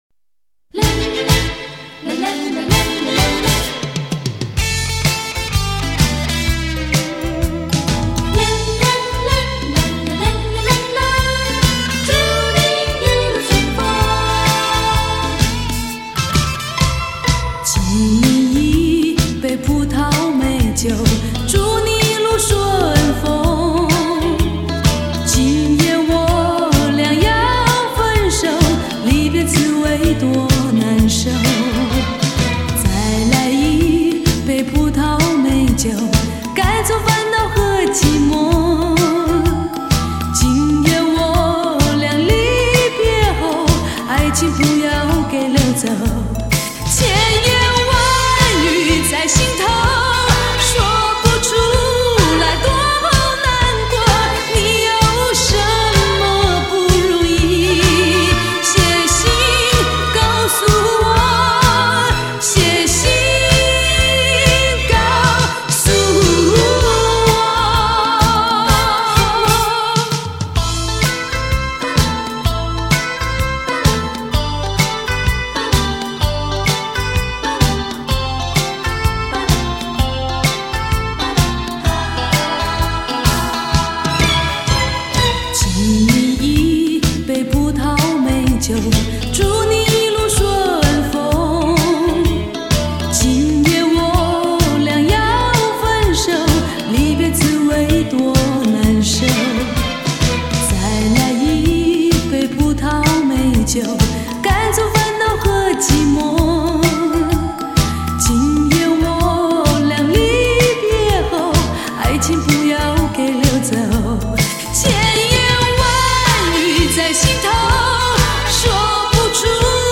台湾最磁性的声音 从心演绎代表作品